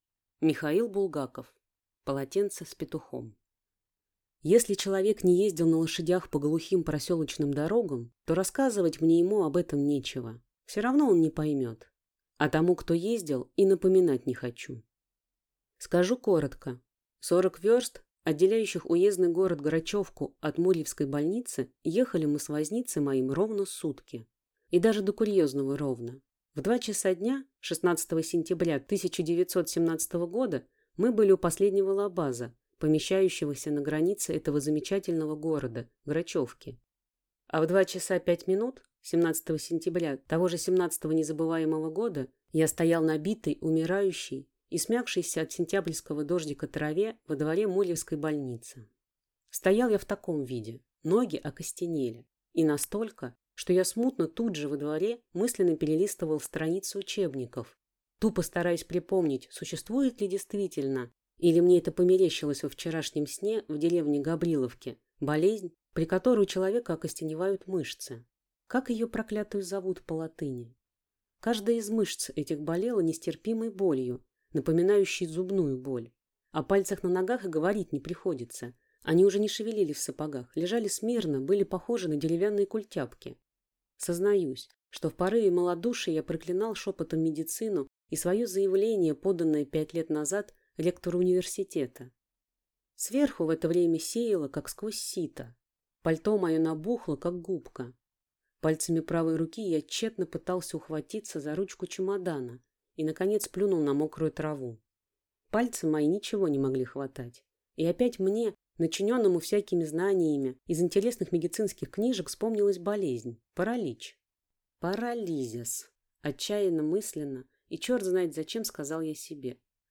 Аудиокнига Полотенце с петухом | Библиотека аудиокниг